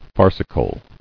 [far·ci·cal]